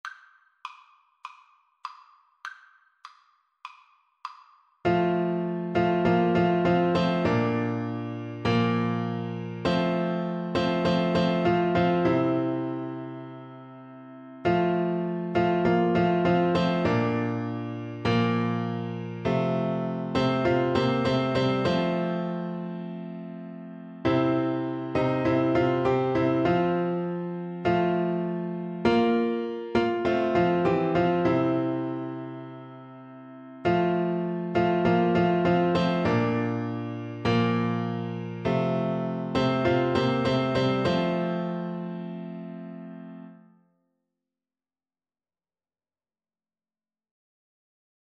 Christian
4/4 (View more 4/4 Music)
Classical (View more Classical Clarinet Music)